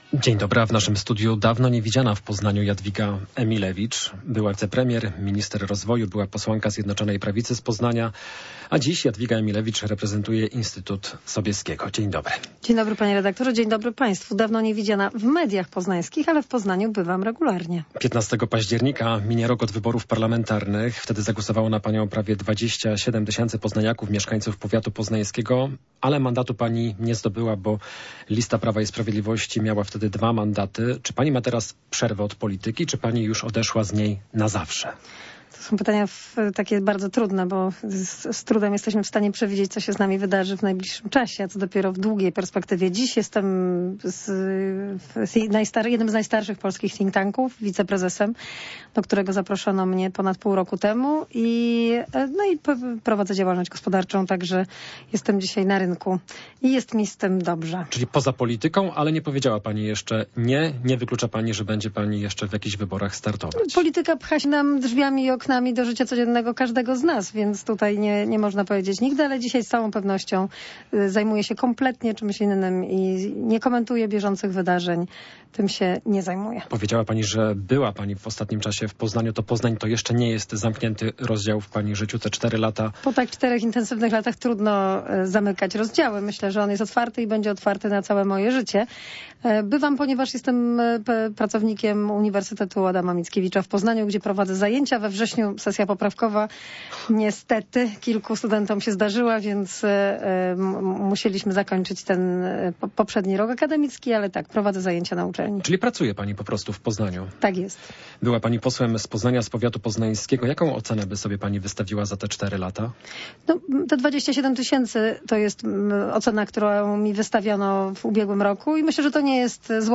Popołudniowa rozmowa - Jadwiga Emilewicz - 08.10.2024